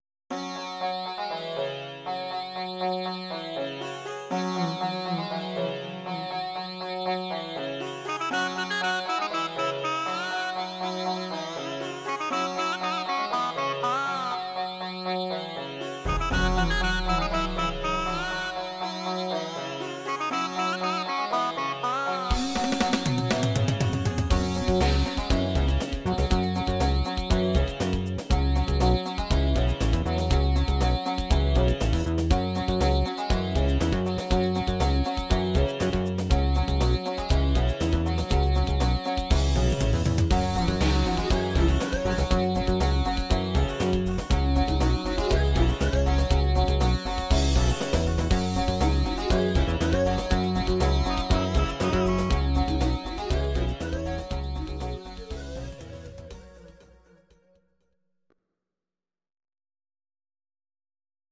Audio Recordings based on Midi-files
Pop, Musical/Film/TV